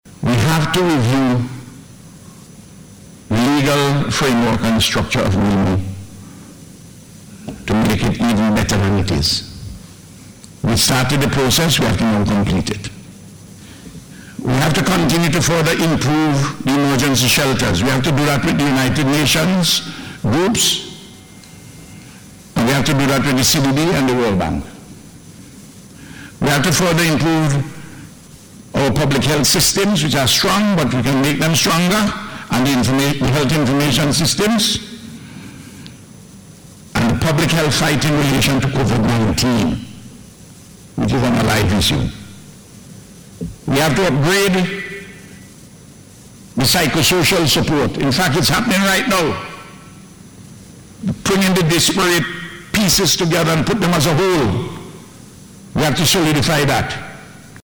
The Prime Minister was contributing to debate on the Supplementary Appropriation Bill 2021, which was passed in Parliament.